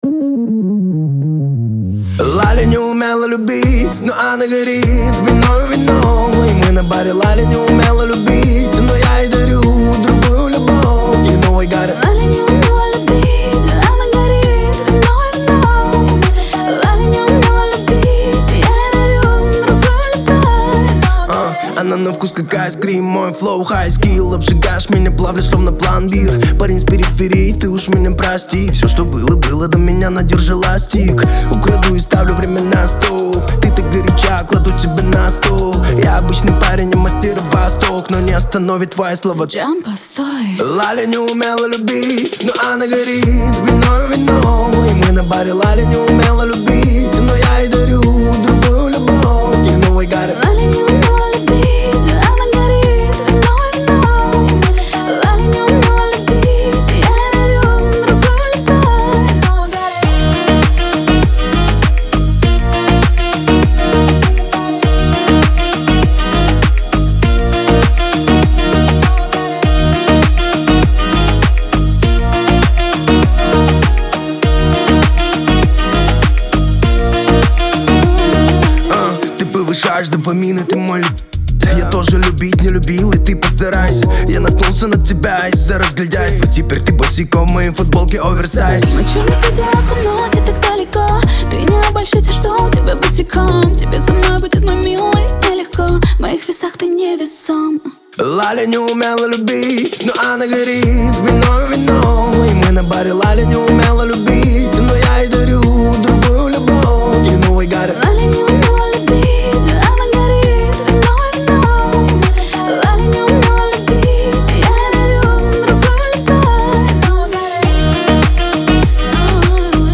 Лирика , pop , Веселая музыка